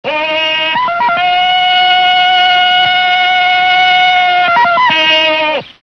Le yodle de la jungle.